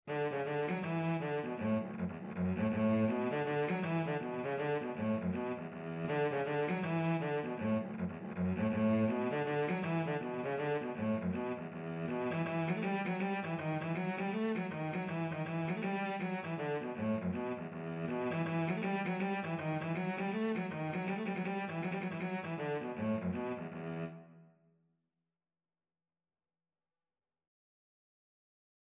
Cello version
E minor (Sounding Pitch) (View more E minor Music for Cello )
4/4 (View more 4/4 Music)
Traditional (View more Traditional Cello Music)